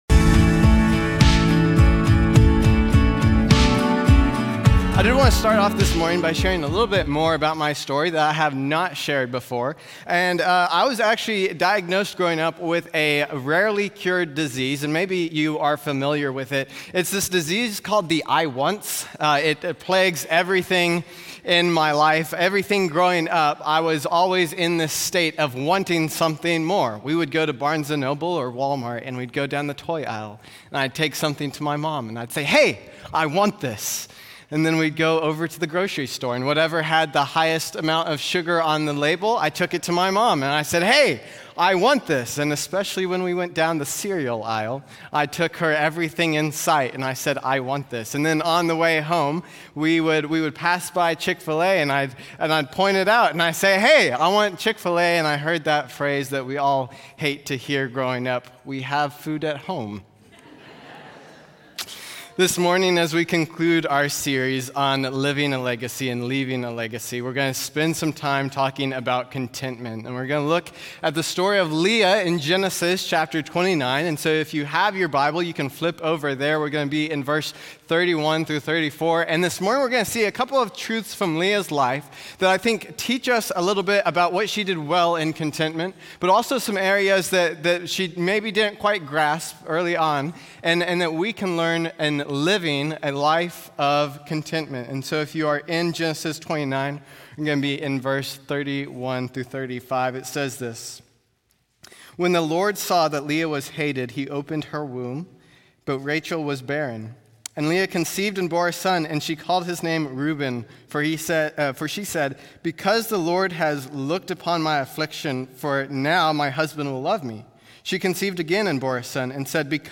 In this sermon from Genesis 29:31–35